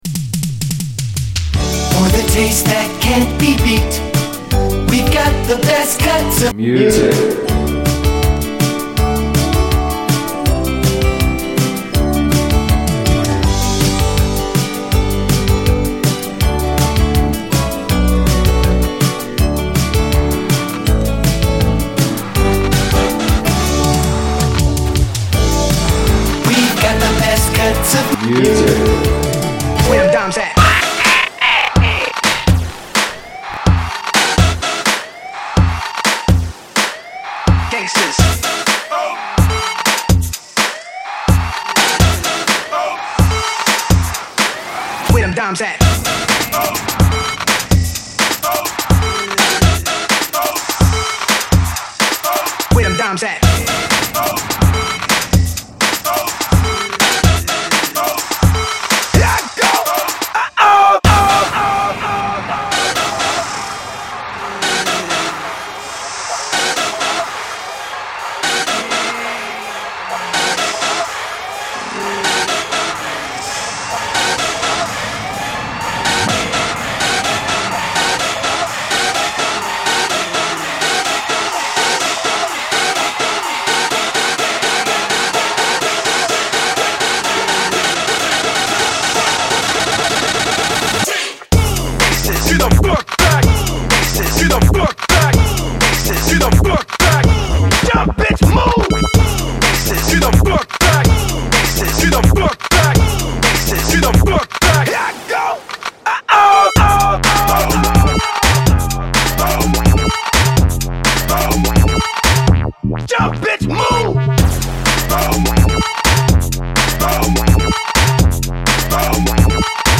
This episode is chock full of excellent tunes to fit your safari lifestyle. It’s also filled with all of the wonders and delights that Africa has to offer, from flora and fauna, to delightful treats and hot eats.